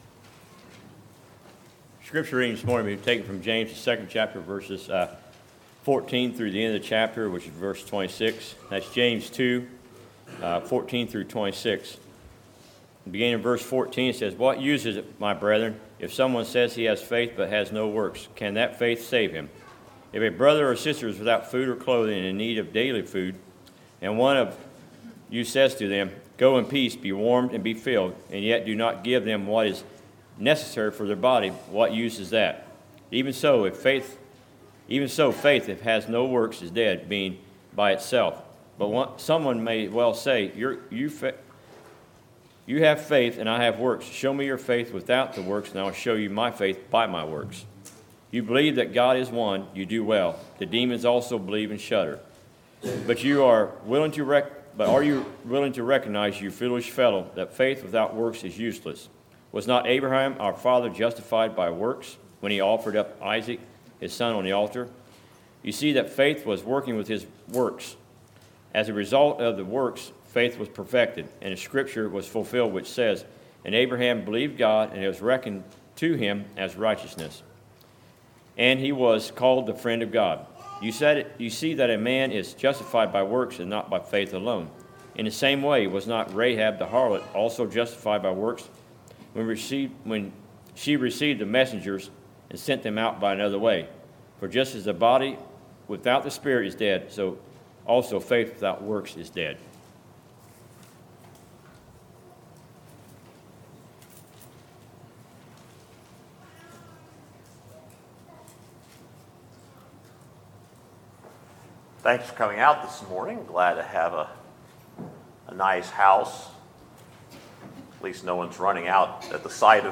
Sermons, June 3, 2018